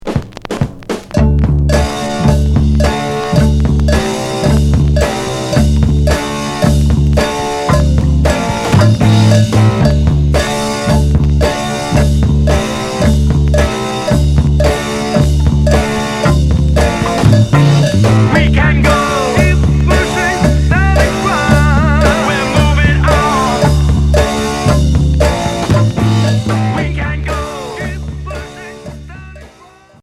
Heavy prog